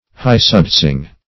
high-sudsing - definition of high-sudsing - synonyms, pronunciation, spelling from Free Dictionary